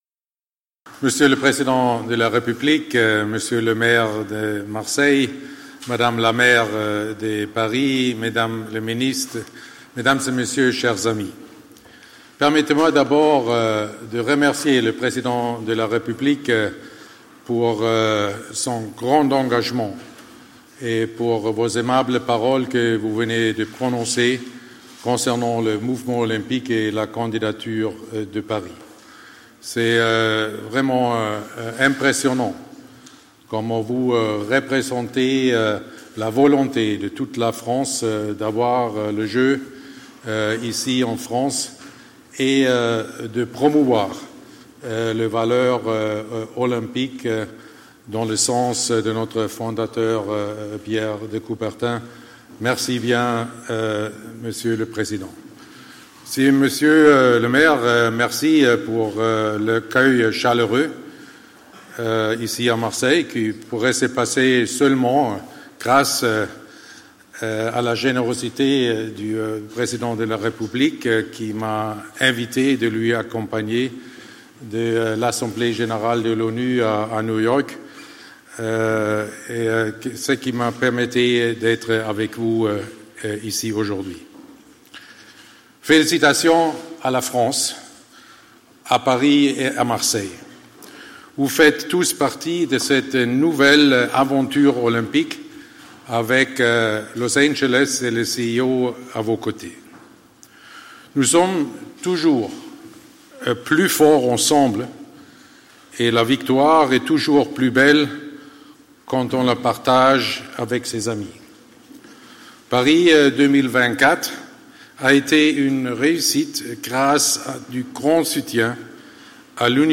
son_copie_petit-166.jpgThomas Bach: “Allez les Bleus” Le propos du Président du Comité International Olympique s’est inscrit dans l’humour.
ste-053_discours_thomas_bach.mp3